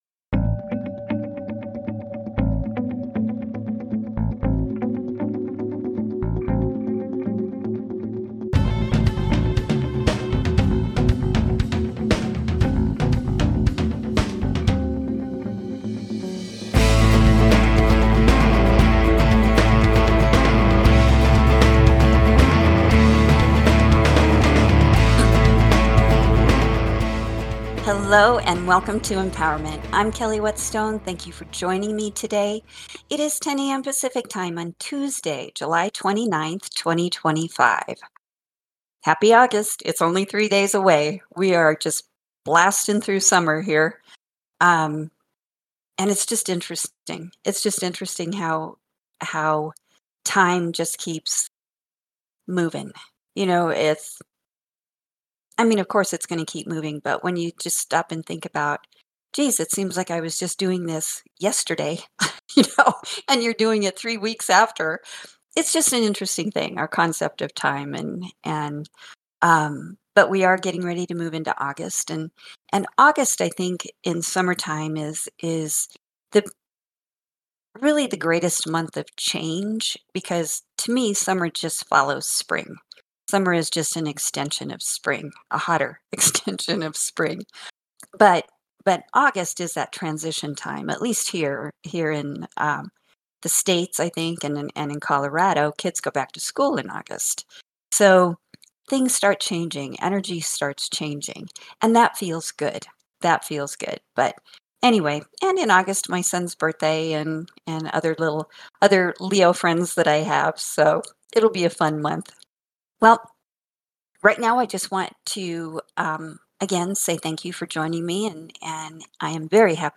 From inspiring guests to energetic power tools it is all about expanding your awareness of who you really are and allowing that awareness to work for you in your day to day life.